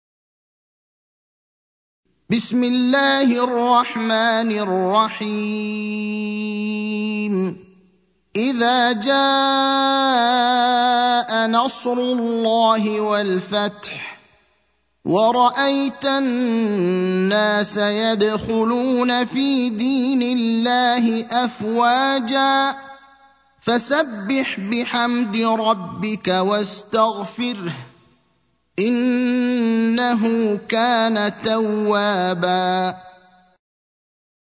المكان: المسجد النبوي الشيخ: فضيلة الشيخ إبراهيم الأخضر فضيلة الشيخ إبراهيم الأخضر النصر (110)